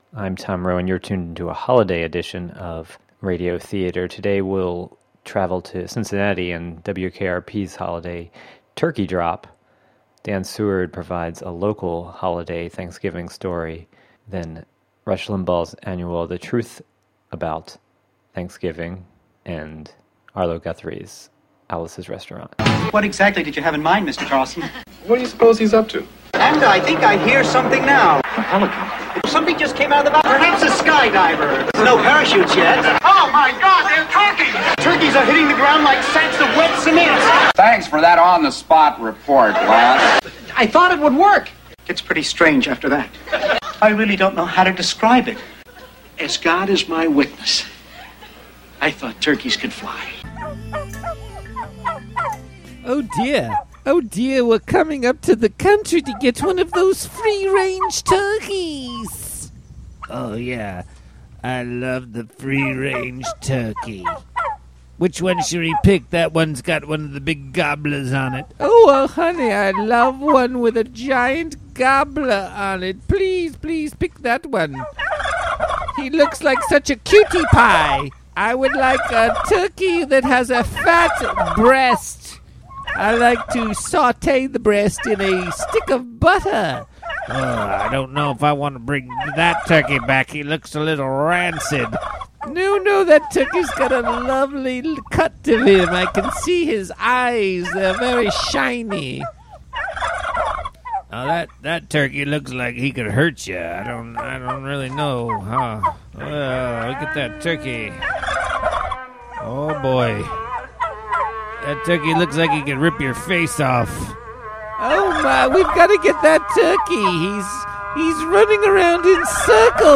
Radio Theatre: I Thought Turkeys Could Fly (Audio)